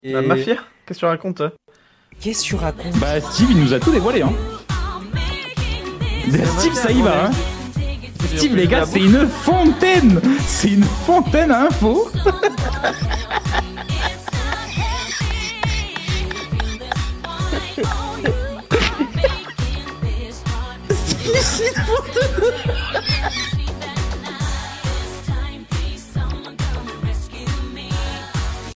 un remix bad laid de la musique